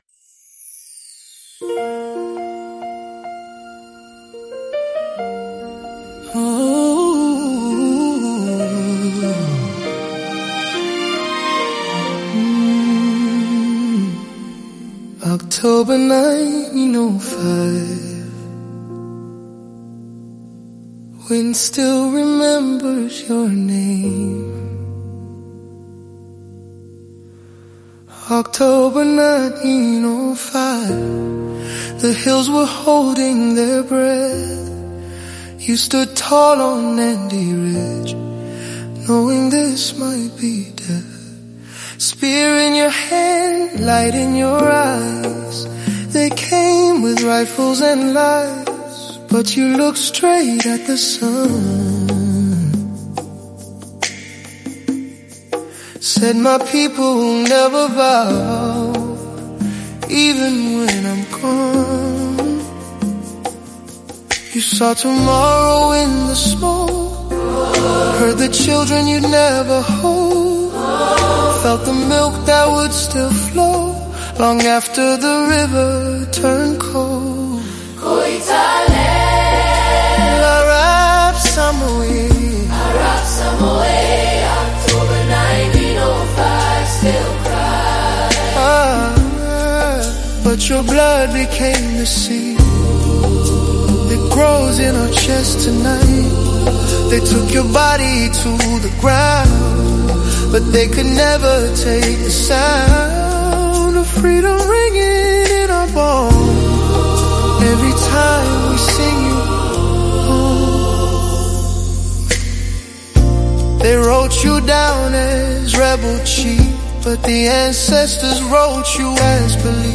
AI-composed music honoring Koitaleel Samoei and the larger House of Turgat
These songs breathe life into ancient Kalenjin rhythms, prophetic chants, and warrior spirit — composed with AI as a collaborator, honoring those who held the line.
Koitaleel's spirit · haunting October tributeition · drums & chant